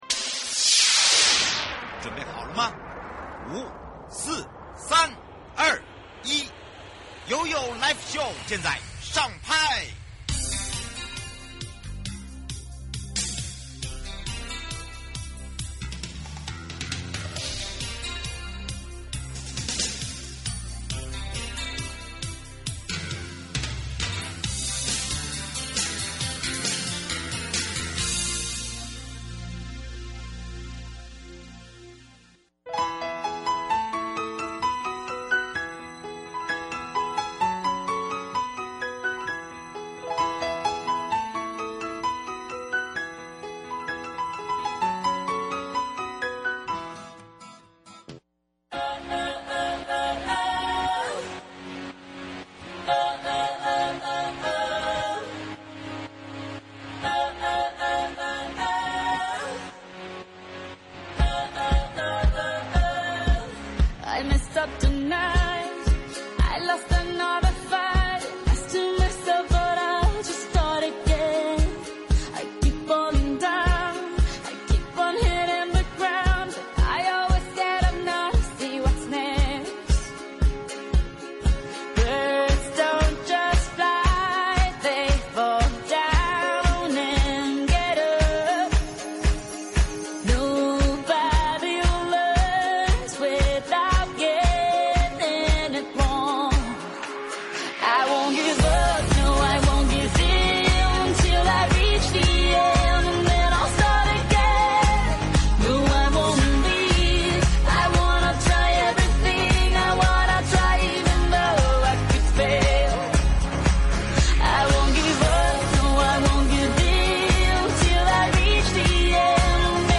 東北角觀光產業齊力宣導旅遊安全! 受訪者：